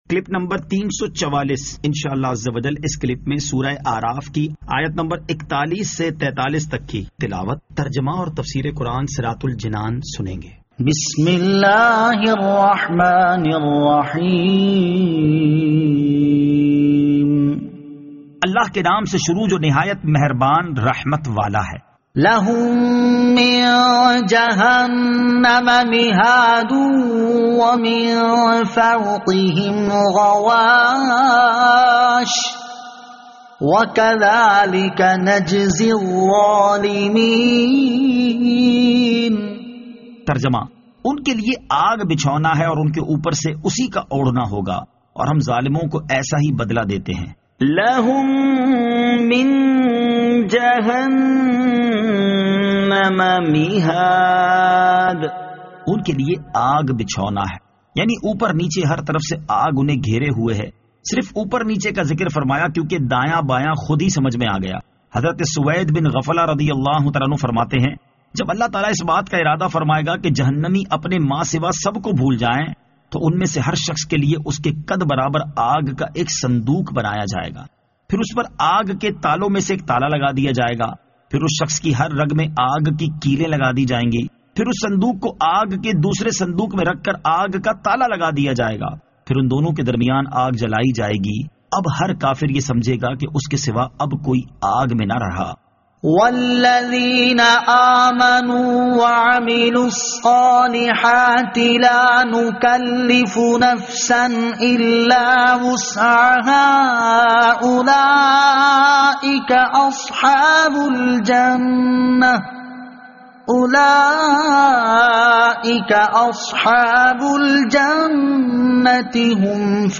Surah Al-A'raf Ayat 41 To 43 Tilawat , Tarjama , Tafseer
2021 MP3 MP4 MP4 Share سُوَّرۃُ الْاَعْرافْ آیت 41 تا 43 تلاوت ، ترجمہ ، تفسیر ۔